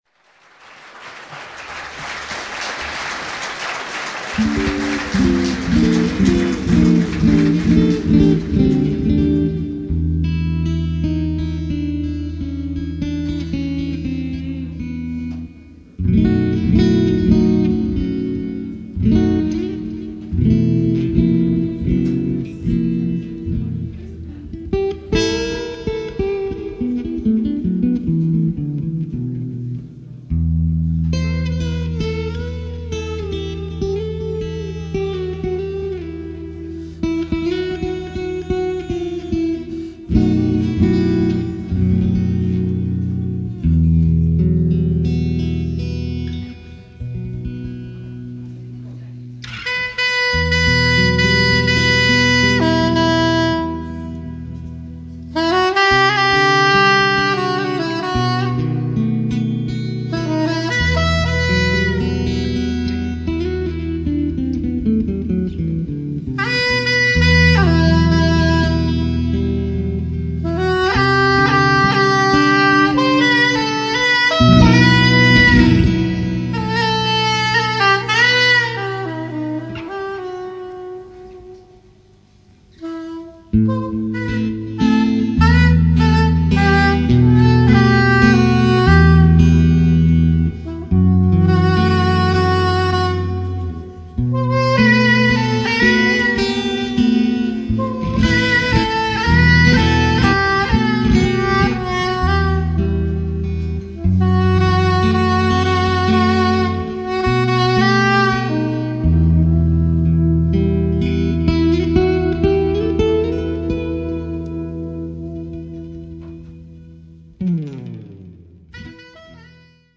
Guitars, vocals
Wind instruments, vocals